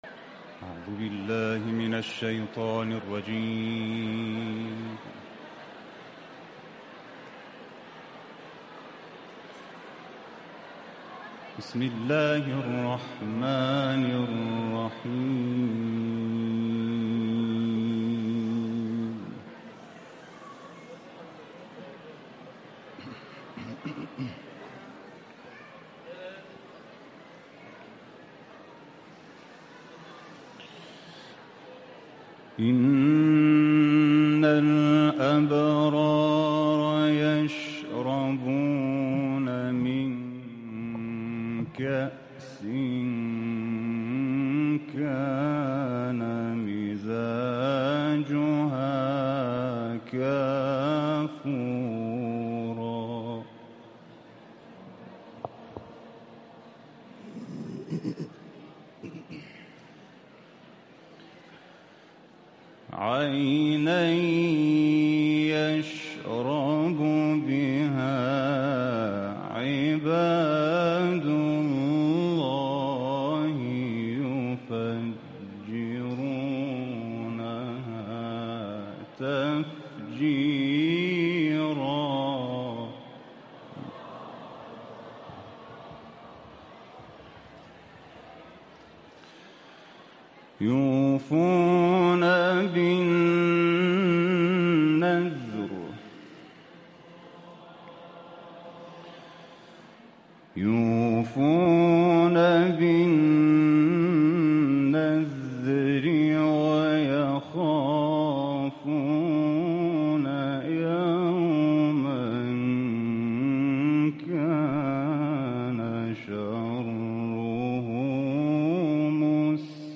شرکت‌کنندگان چهلمین دوره مسابقات بین‌المللی قرآن کریم جمهوری اسلامی ایران در حسینیه امام خمینی(ره) با رهبر معظم انقلاب دیدار کردند.
این قاری بین‌المللی، آیاتی از سوره مبارکه انسان را تلاوت کرد.